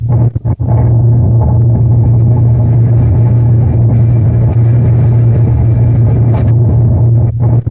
洗衣机的卷对卷磁带循环记录" washeavy
描述：在1/4"磁带上录制的坑洼不平的颤音、无人驾驶的洗衣机和物理循环（循环它）
标签： 雄蜂 线头 音调下降 带环 洗衣机
声道立体声